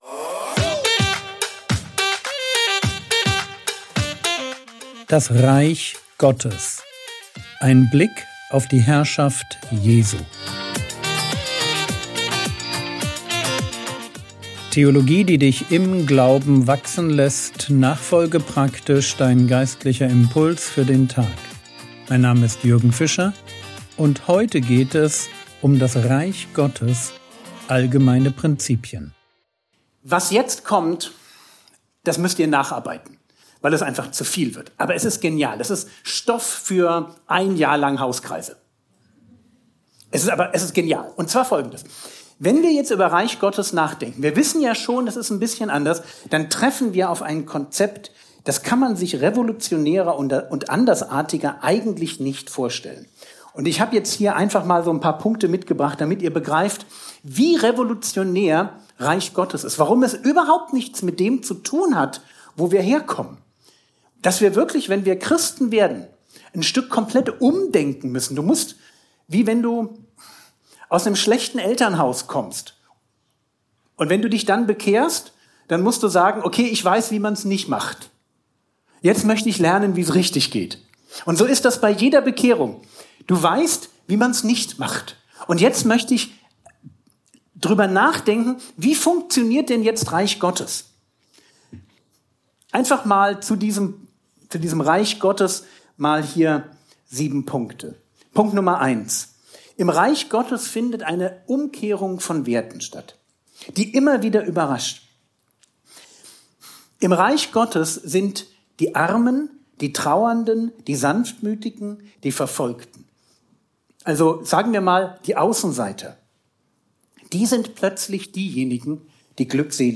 Vortrag Paderborn